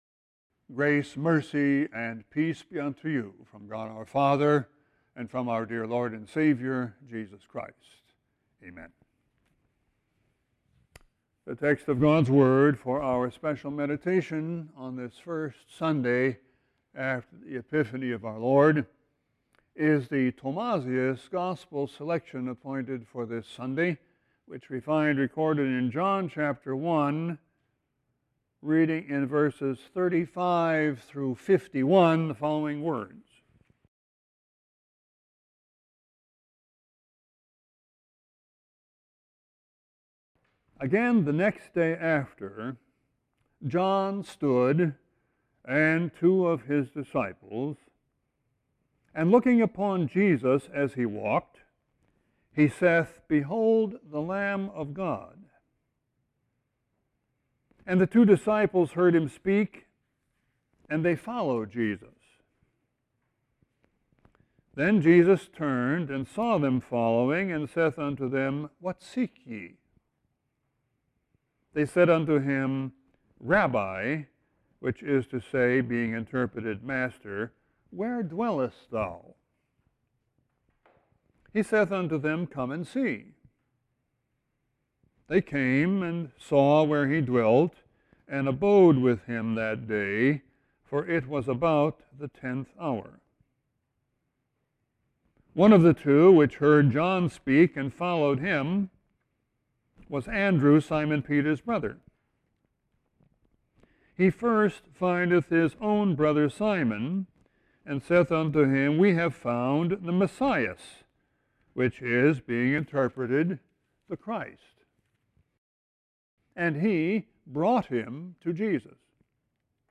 Sermon 1-8-17.mp3